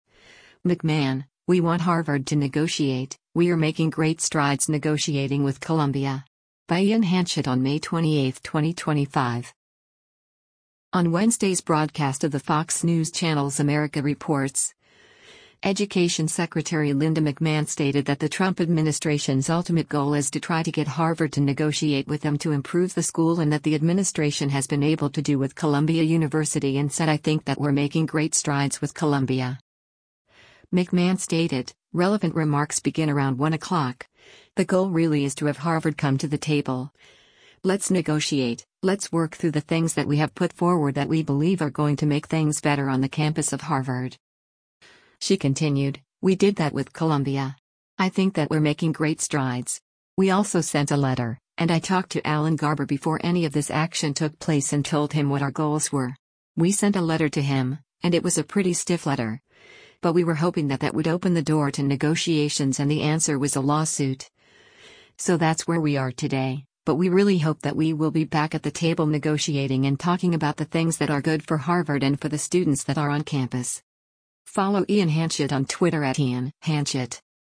On Wednesday’s broadcast of the Fox News Channel’s “America Reports,” Education Secretary Linda McMahon stated that the Trump administration’s ultimate goal is to try to get Harvard to negotiate with them to improve the school and that the administration has been able to do with Columbia University and said “I think that we’re making great strides” with Columbia.